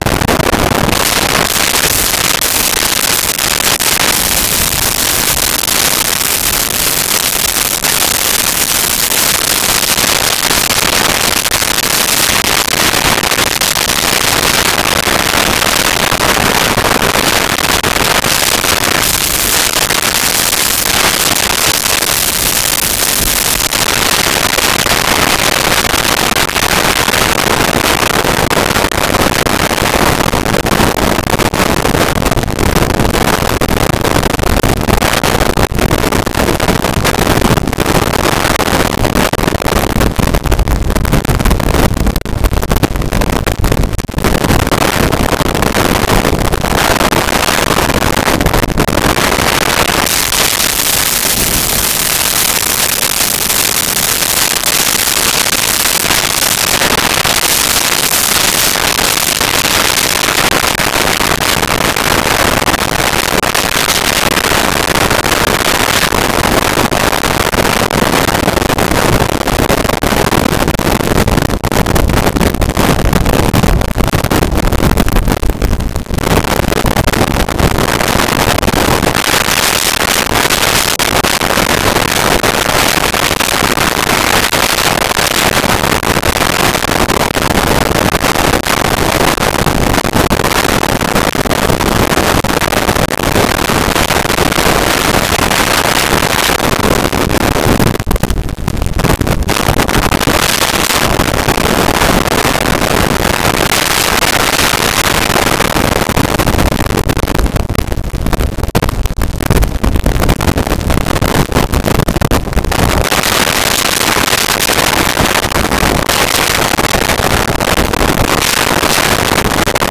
Thunder Light Rain
Thunder Light Rain.wav